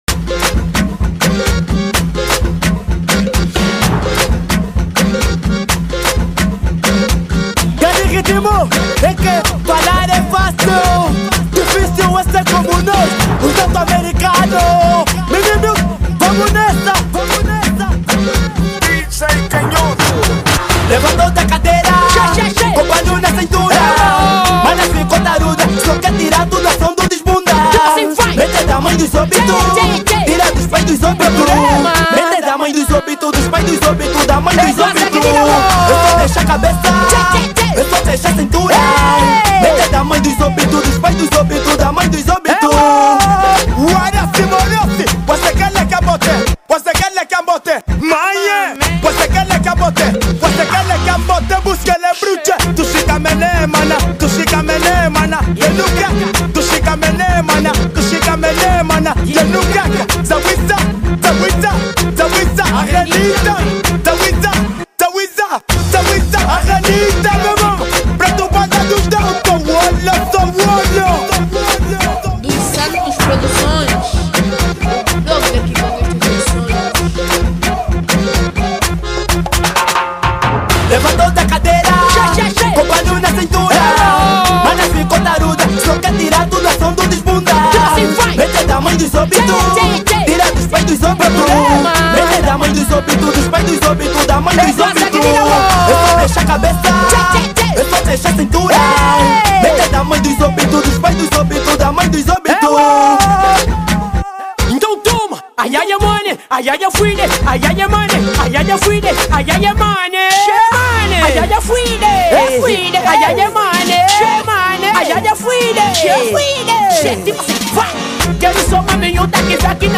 Categoria: Afro House